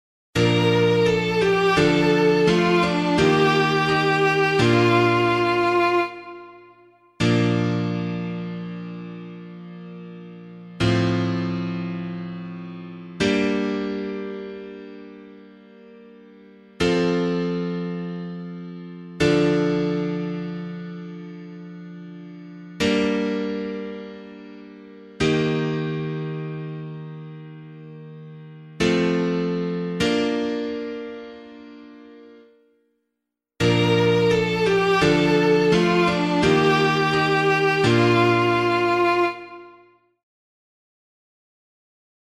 Responsorial Psalm     Psalm 86 (85): 5-6, 9-10, 15-16a
B♭ major